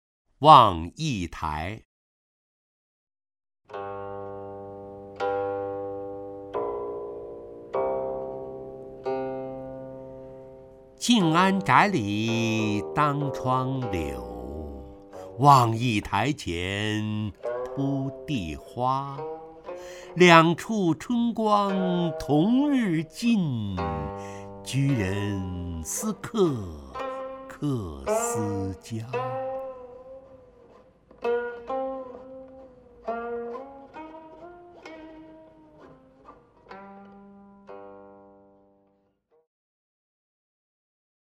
陈醇朗诵：《望驿台》(（唐）白居易) （唐）白居易 名家朗诵欣赏陈醇 语文PLUS